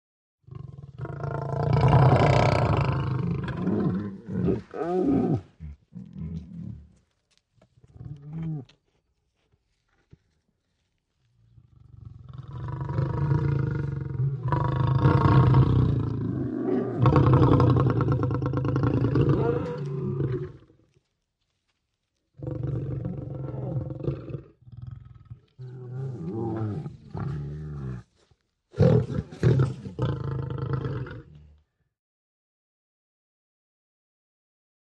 ANIMALS WILD: Snarling group of lions.